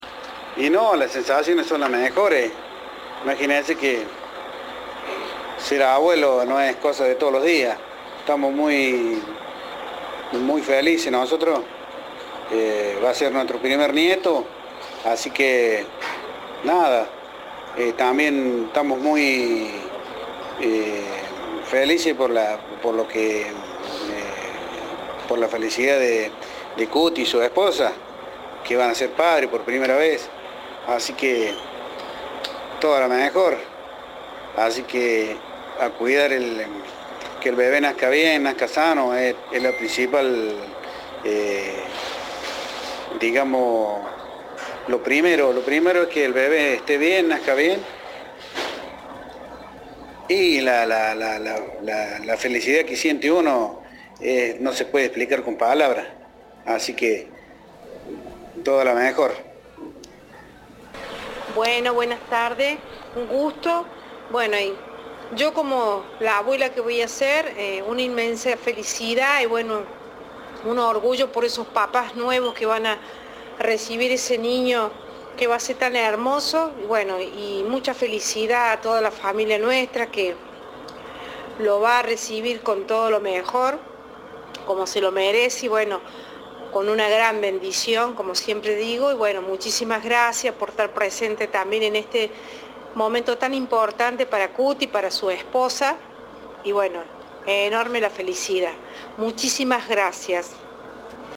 Entrevista de Tiempo de Juego.